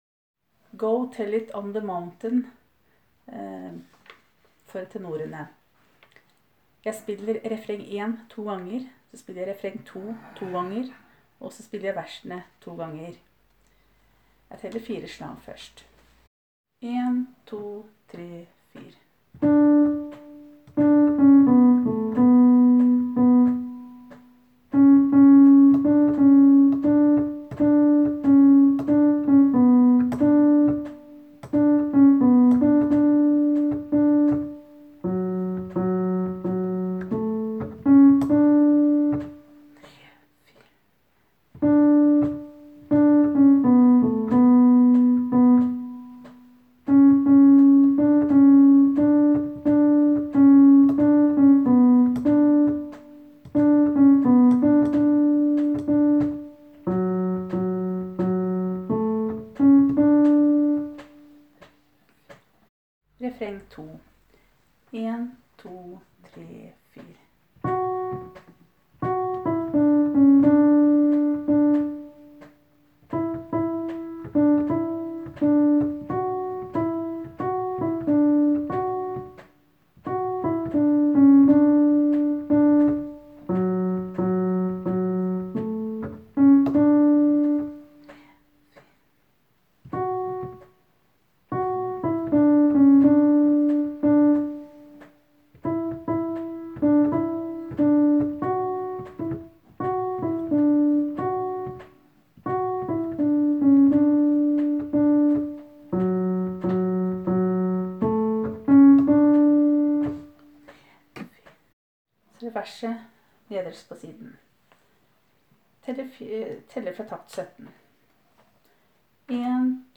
Jul 2017 Tenor (begge konserter)